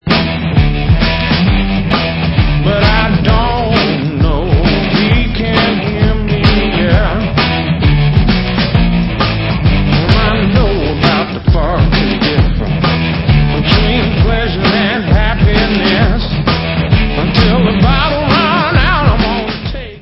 sledovat novinky v oddělení Blues